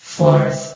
CitadelStationBot df15bbe0f0 [MIRROR] New & Fixed AI VOX Sound Files ( #6003 ) ...
fourth.ogg